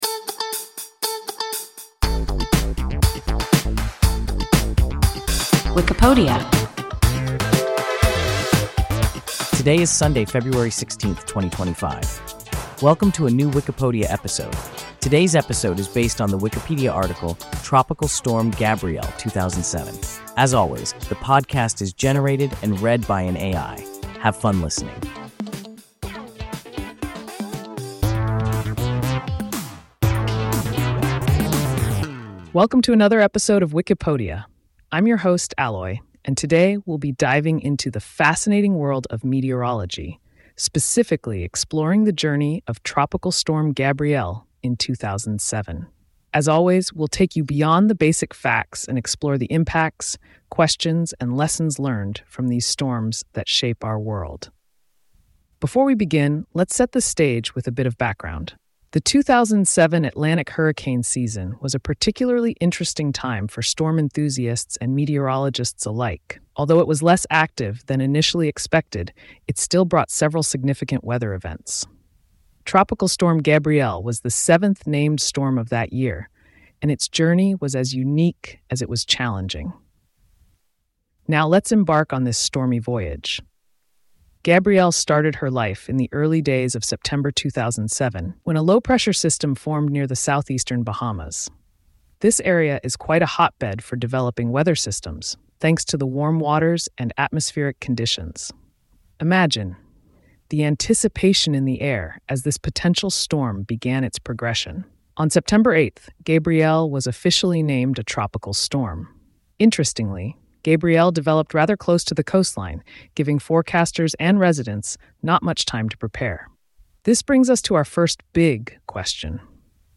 Tropical Storm Gabrielle (2007) – WIKIPODIA – ein KI Podcast